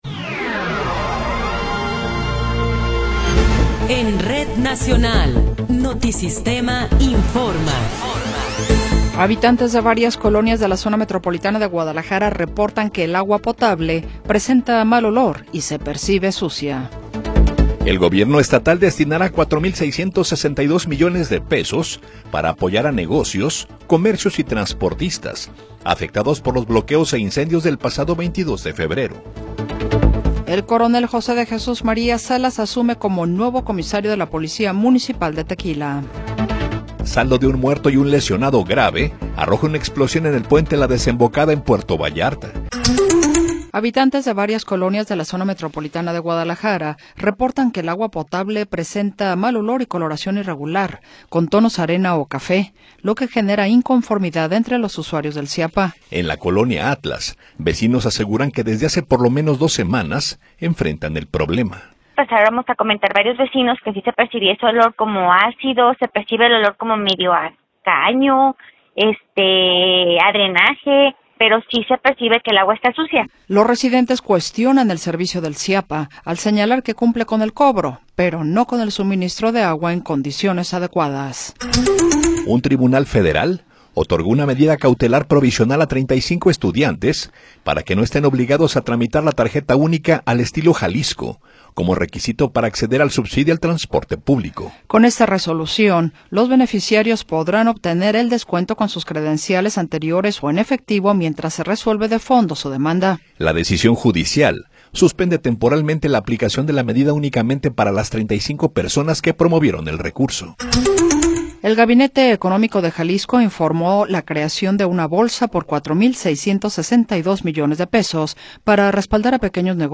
Noticiero 20 hrs. – 3 de Marzo de 2026